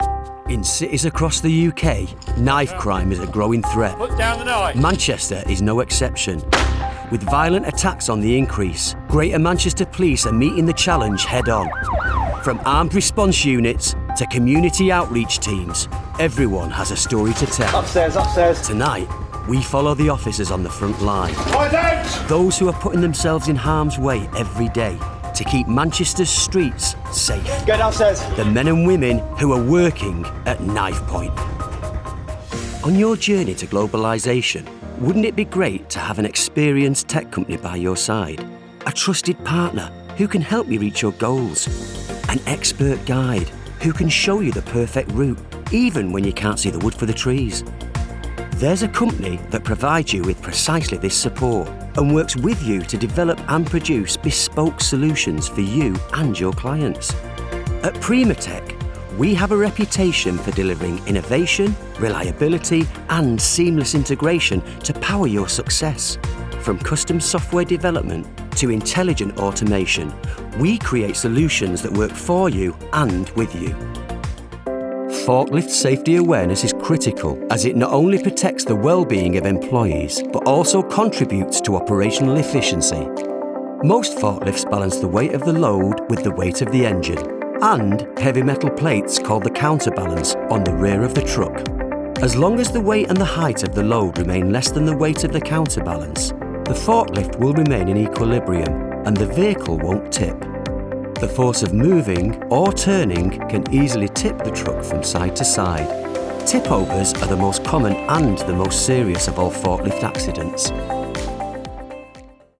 Narration Showreel
Known for their honest delivery and down-to-earth charm, they bring scripts to life with heartfelt narration and compelling character performances.
Male
Manchester
Gravitas
Husky (light)